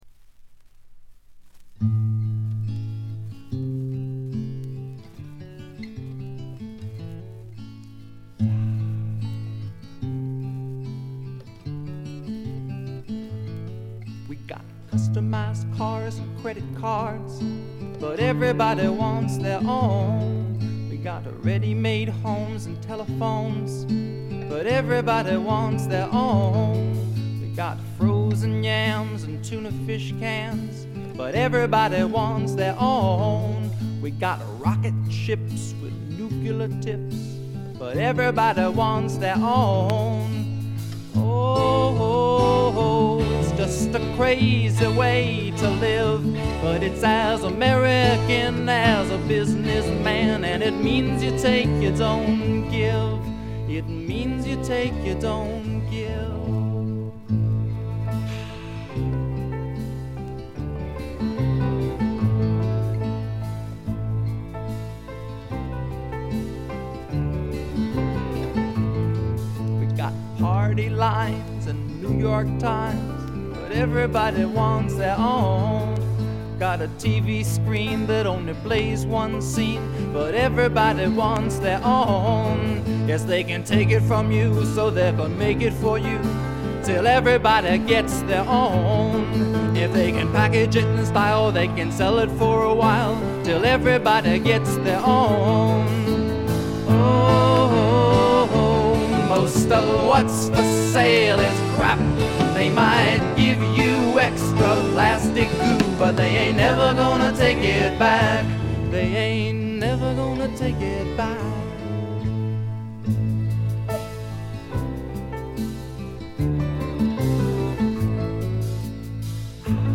軽微なチリプチ少々。
しかし内容はいたってまともなフォーキー・シンガー・ソングライター作品です。
録音はマサチューセッツ。
いかにも東部らしい静謐な空気感がただようなかに、愛すべきいとおしい曲が散りばめられた好盤です。
試聴曲は現品からの取り込み音源です。
Piano, Guitar
Violin, Viola